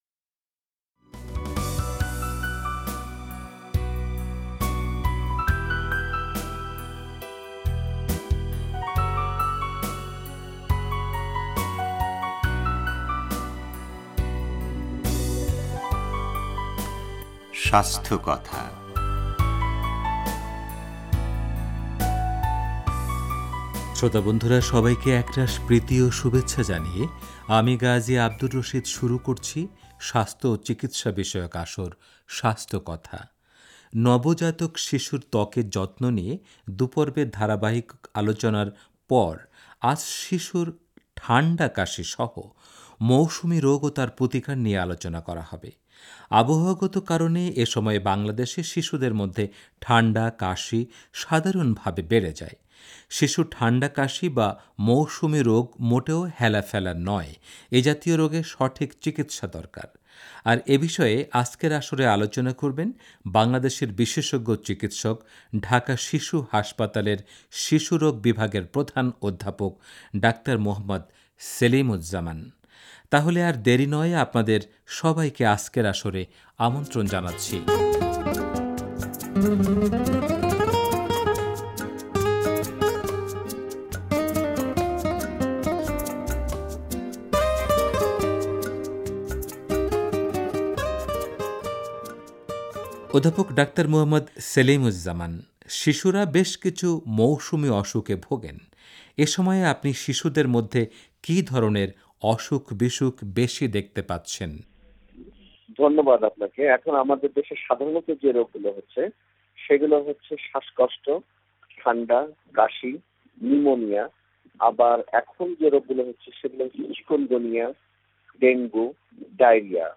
এ নিয়ে রেডিও তেহরানের স্বাস্থ্যকথা অনুষ্ঠানে আলোচনা করেছেন বাংলাদেশের বিশেষজ্ঞ চিকিৎসক